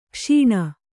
♪ kṣīṇa